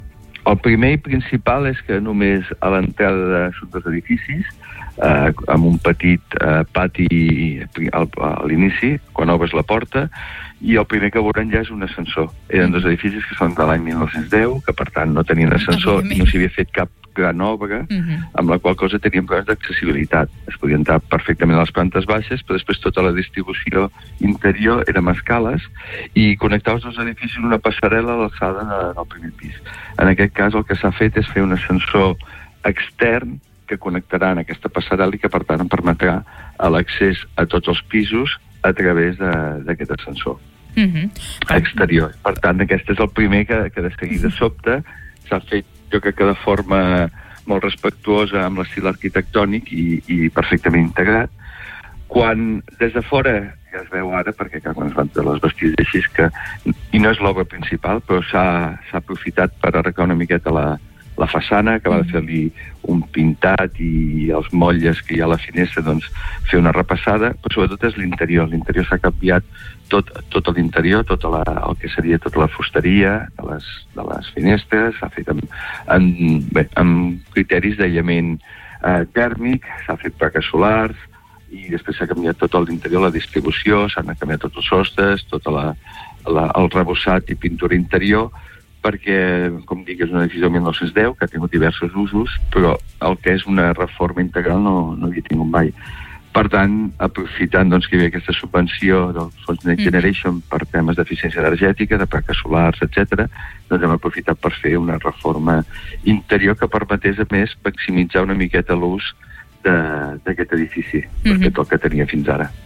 Entrevistes SupermatíNotícies
I per parlar de la reobertura d’aquest edifici ens ha visitat al Supermatí l’alcalde de la Bisbal d’Empordà, Òscar Aparicio.